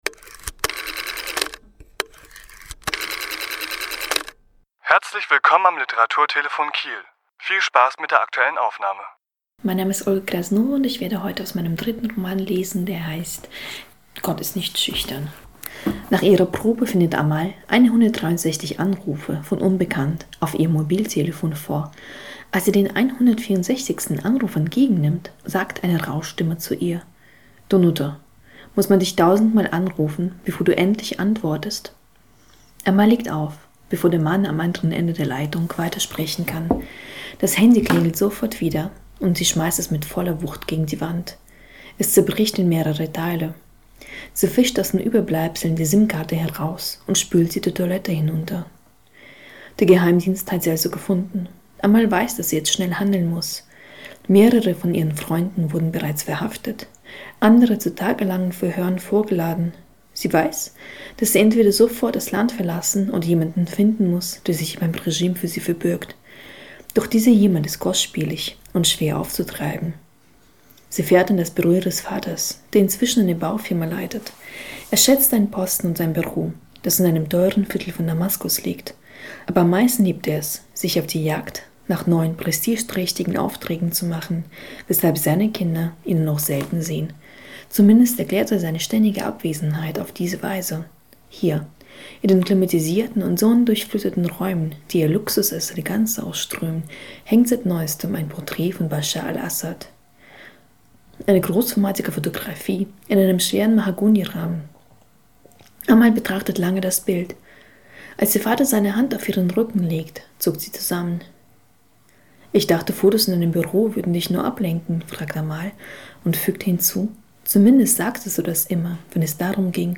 Autor*innen lesen aus ihren Werken
Die Aufnahme entstand bei einer Lesung im Literaturhaus Schleswig-Holstein am 24.4.2017.